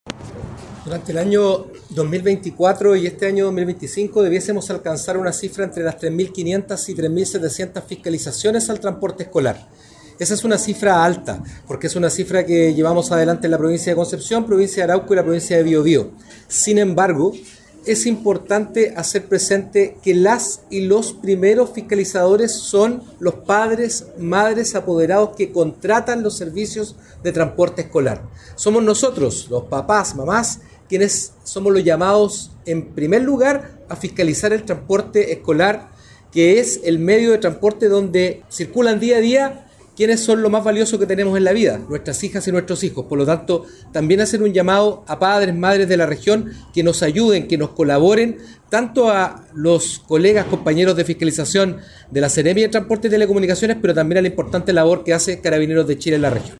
Cuna-1-Seremi-de-transporte-Patricio-Fierro-transporte-escolar.mp3